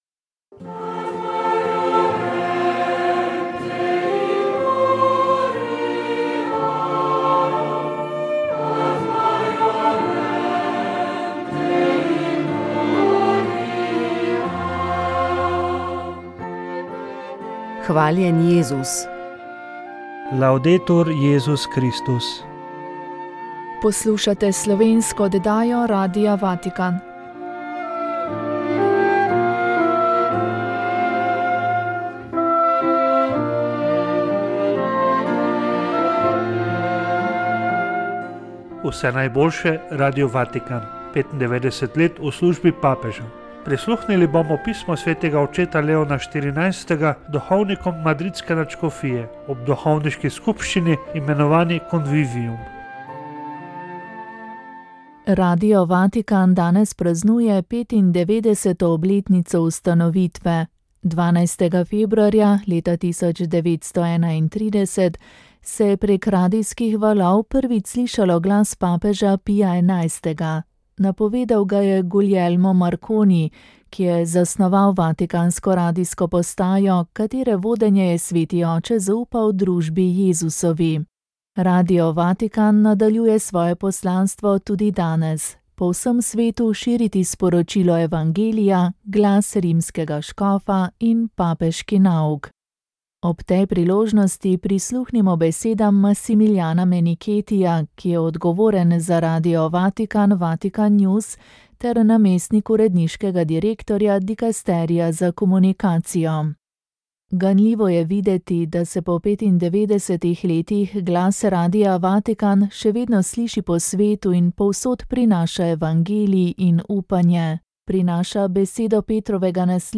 Radijska kateheza
O tem, kaj pomeni svetost življenja, na čem temelji nauk o nedotakljivosti človeškega življenja, zakaj zarodek ni eden od delov materinega telesa in katere stranpoti lahko prinesejo sedanje težnje po evtanaziji, nam je spregovoril nadškof Anton Stres v tokratni radijski katehezi.